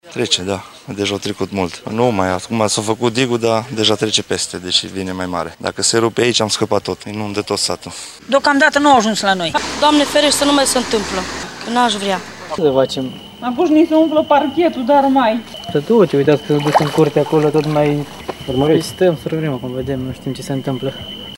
vox-uri-alerta-inundatii-Denta.mp3